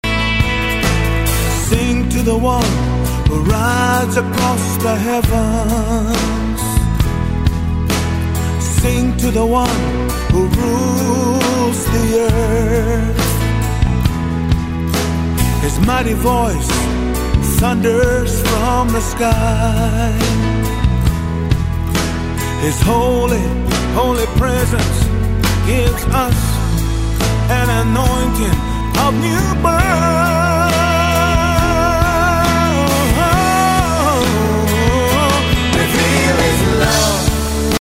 2007's Top Native Gospel Album